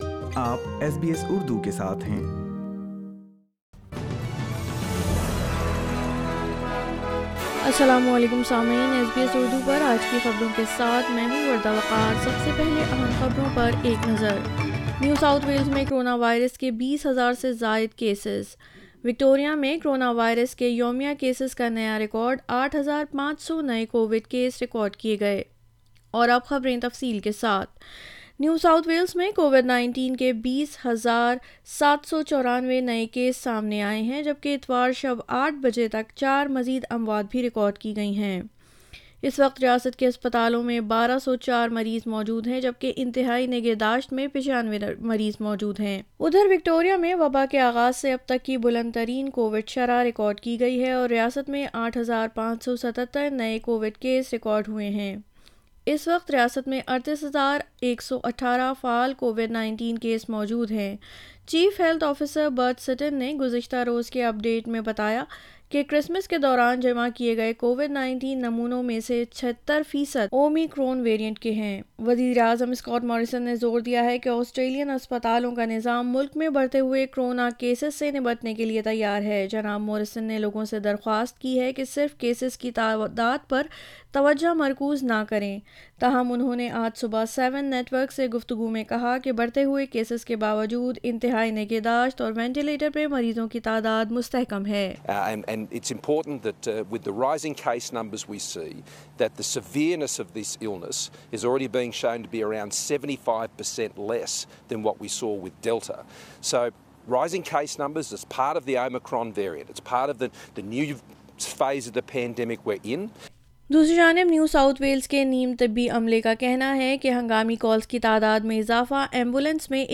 SBS Urdu News 03 January 2022